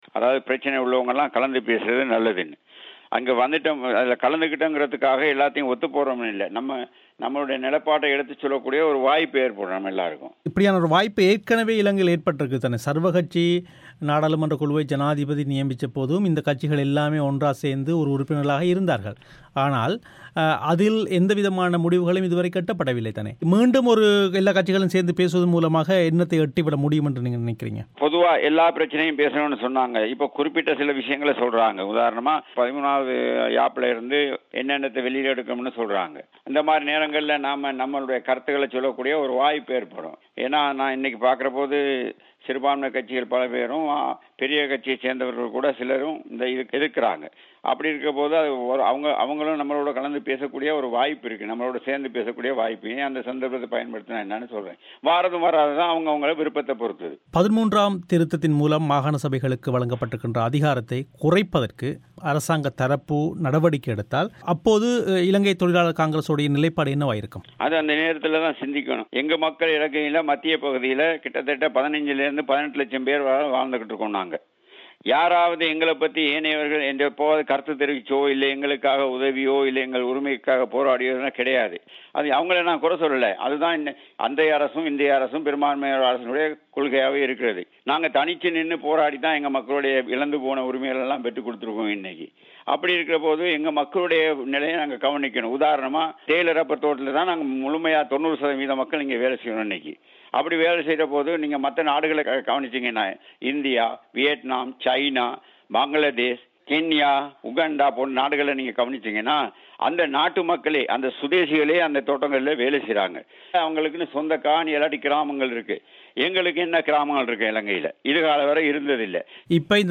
இலங்கையில் மாகாணசபைகளின் அதிகாரங்களைப் பறிக்கும் முயற்சிகளுக்கு எதிராக சிறுபான்மை தமிழ், முஸ்லிம் கட்சிகள் ஒன்றிணைய வேண்டும் என்று சில கட்சிகள் விடுத்துள்ள கோரிக்கை பற்றி, ஆளும் கூட்டணியில் அங்கம் வகிக்கும் இலங்கைத் தொழிலாளர் காங்கிரஸ் தலைவர் அமைச்சர் முத்து சிவலிங்கம் பிபிசி தமிழோசைக்கு அளித்த செவ்வி